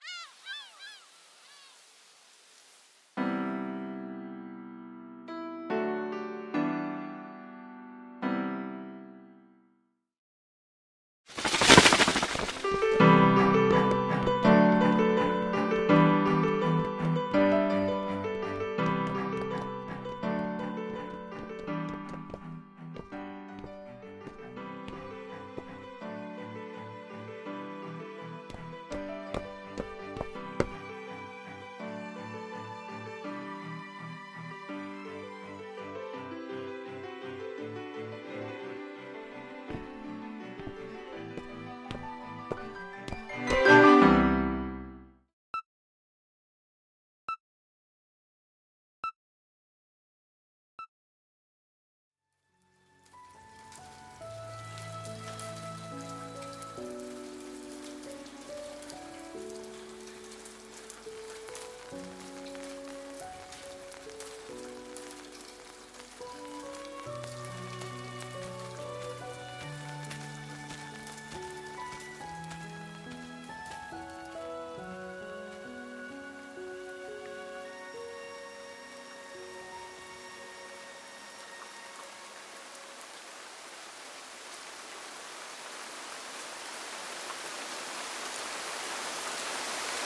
【声劇】ディアオフィ.